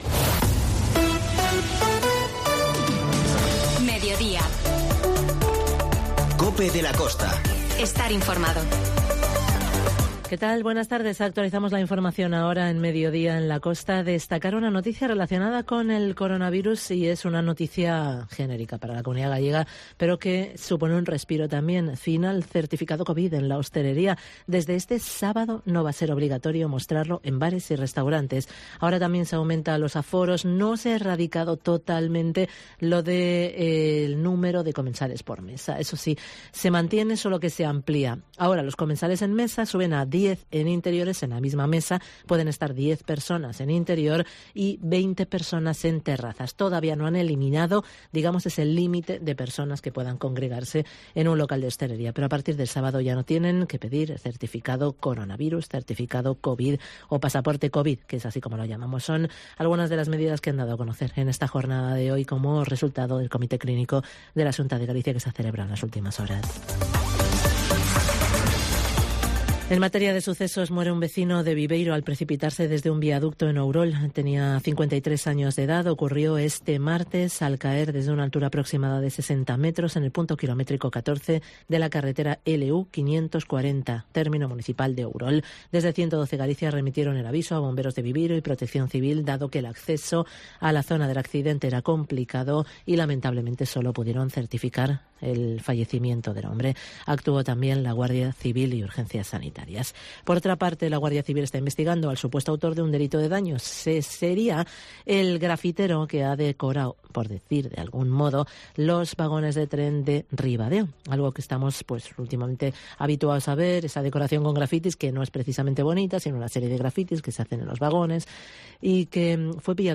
COPE de la Costa - Ribadeo - Foz INFORMATIVO